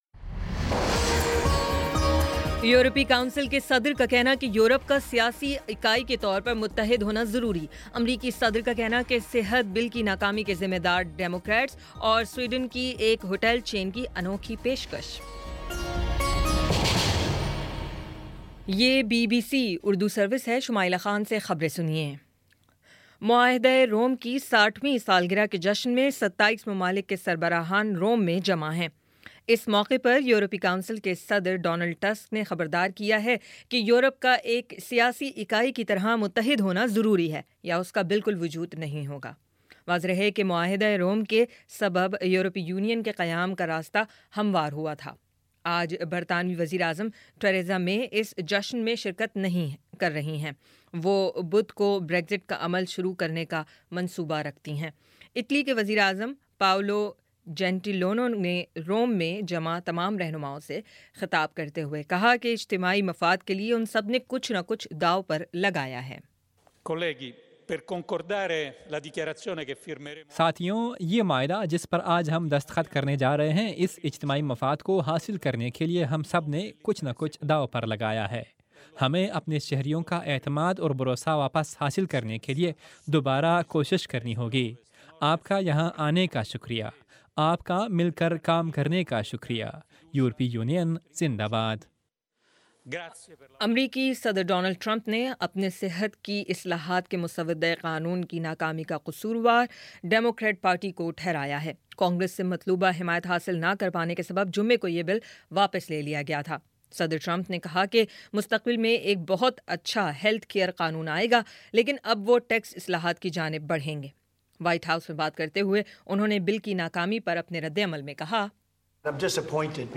مارچ 25 : شام چھ بجے کا نیوز بُلیٹن